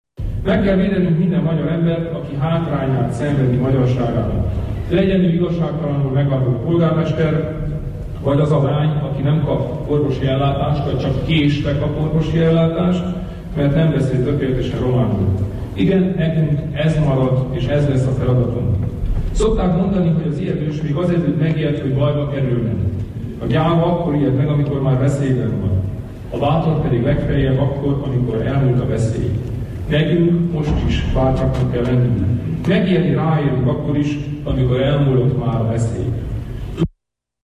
A Romániai Magyar Demokrata Szövetség elnöke a szövetség kisparlamentjének tekintett Szövetségi Képviselők Tanácsa mai marosvásárhelyi ülésén tartott politikai helyzetértékelésében beszélt erről.